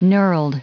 Prononciation du mot knurled en anglais (fichier audio)
Prononciation du mot : knurled